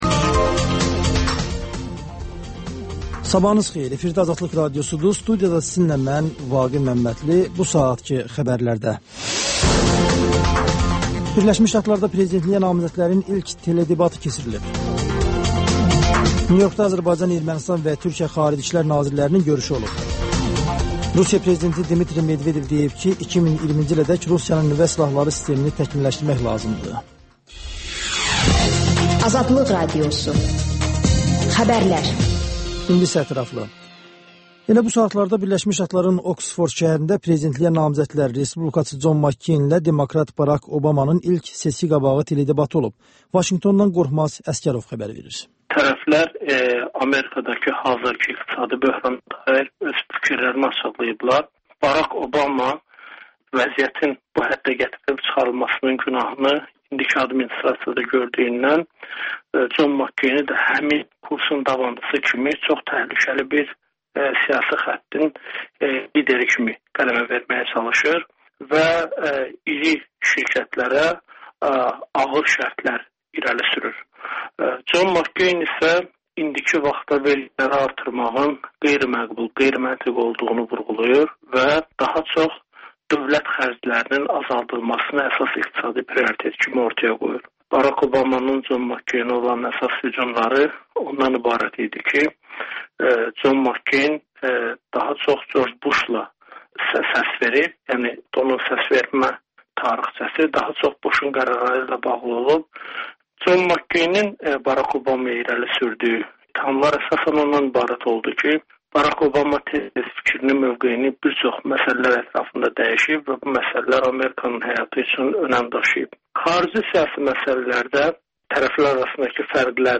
Xəbərlər, müsahibələr, hadisələrin müzakirəsi, təhlillər, sonda HƏMYERLİ rubrikası: Xaricdə yaşayan azərbaycanlılar haqda veriliş